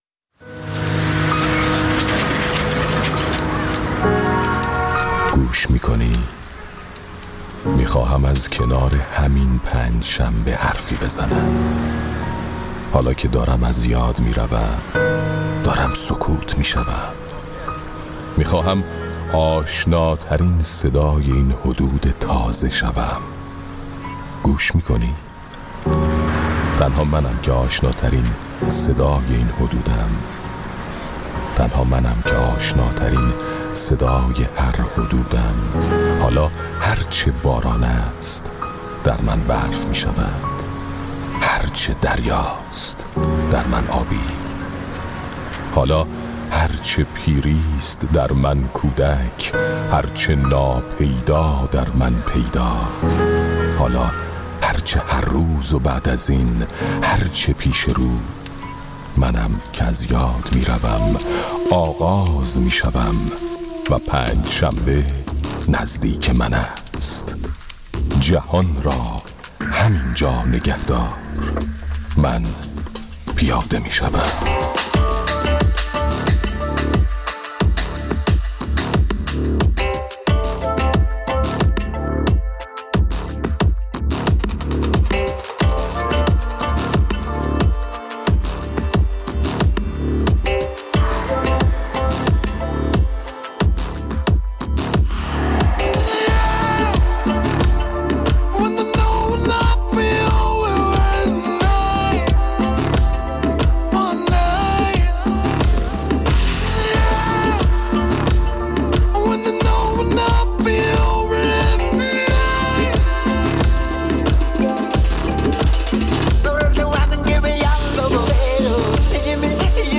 در این ایستگاه فردا ادامه نظرات شنوندگان برنامه را در مورد تعریف جدید رهبر ایران از واژه مستضعف می‌شنویم.